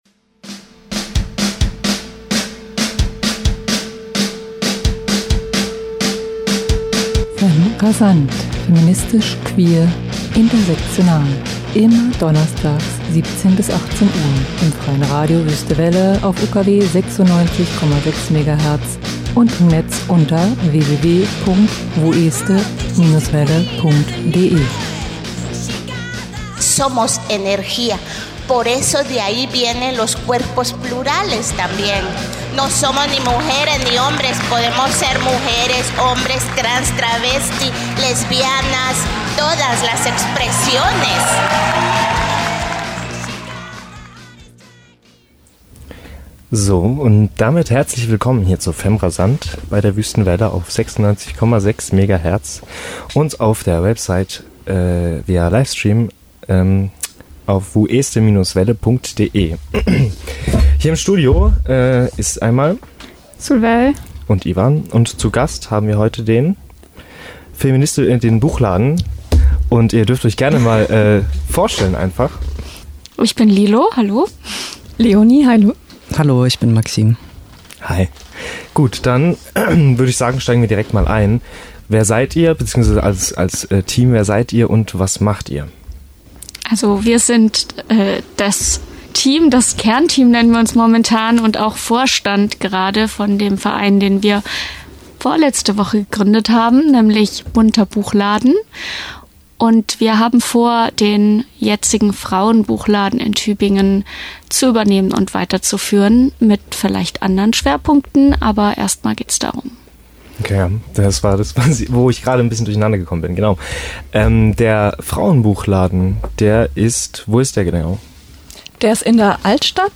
In unserem heutigen Interview sprachen wir mit drei Mitstreiter*innen des Projekts über ihre Pläne, Ideen und Visionen.